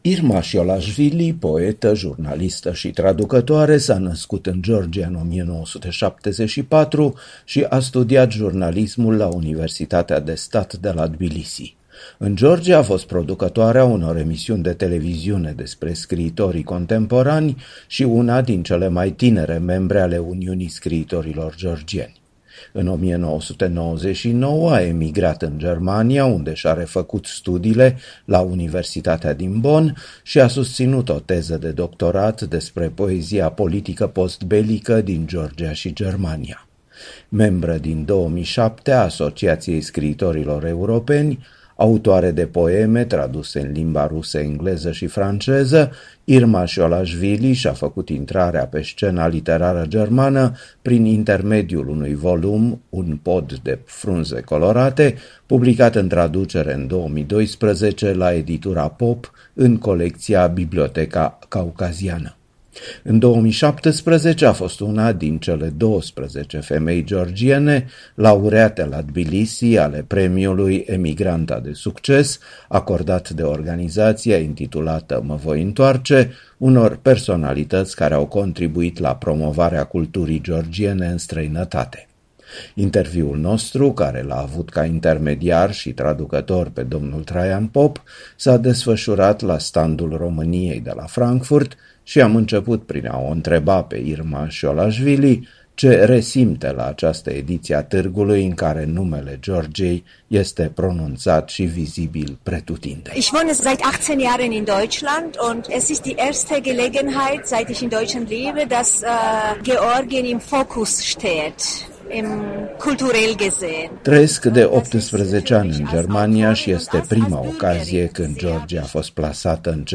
Un interviu cu o poetă, jurnalistă și traducătoare georgiană stabilită în Germania.